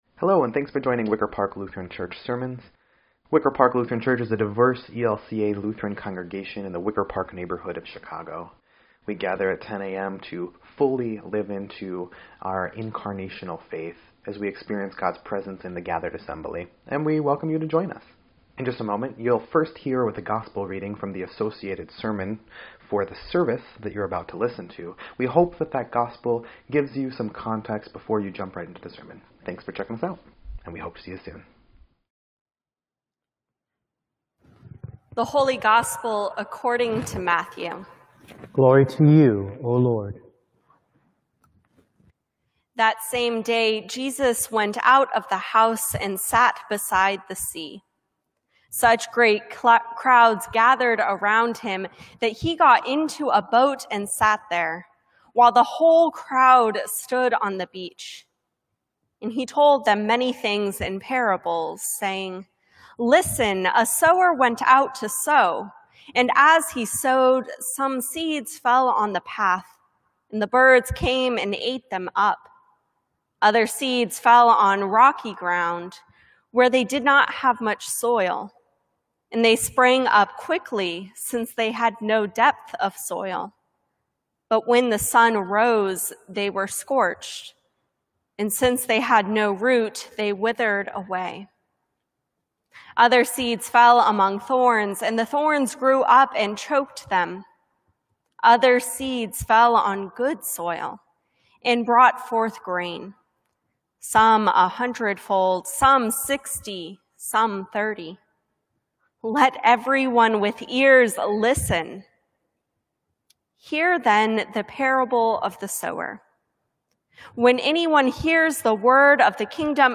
7.12.20_Sermon_EDIT.mp3